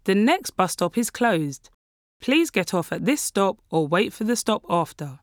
nextstopclosed.wav